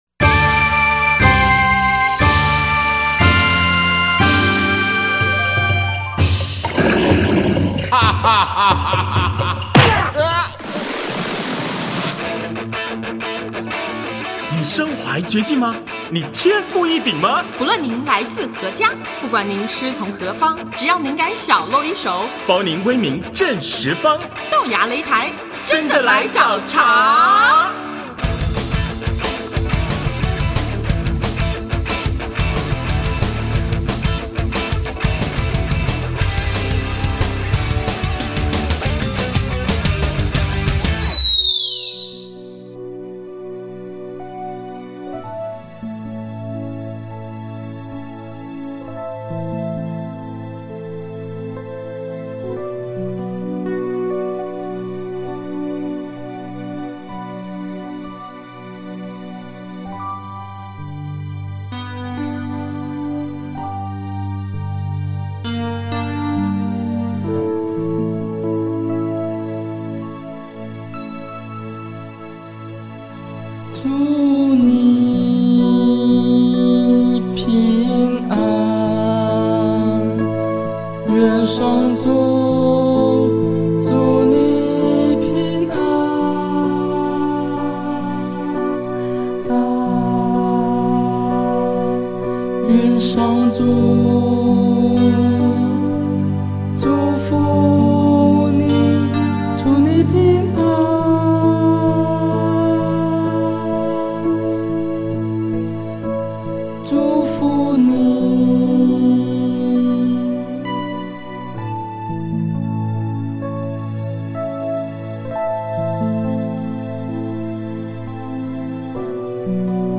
【豆芽擂台】220|专访望乐团(五)：学习与分辨